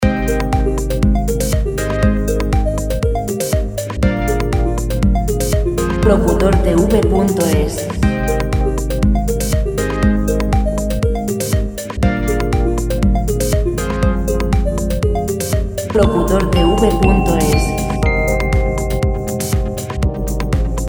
Música  pop libre de derechos de autor.